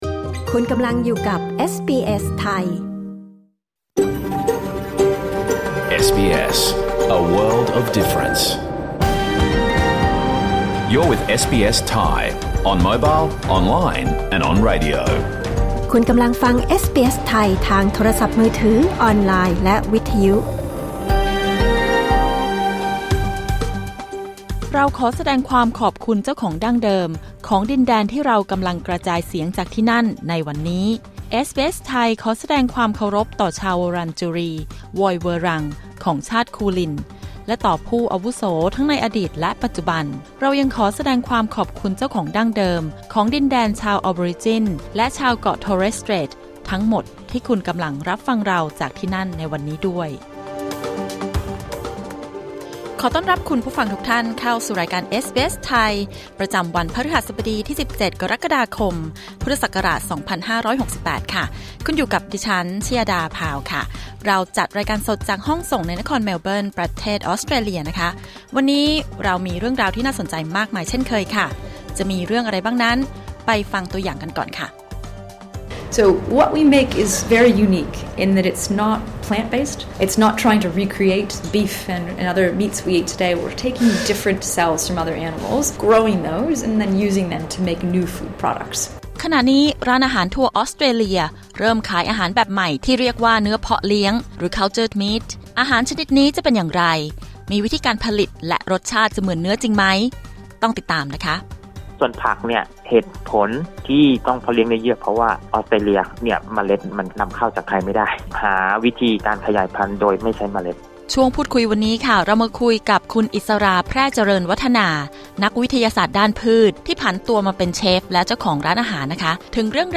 รายการสด 17 กรกฎาคม 2568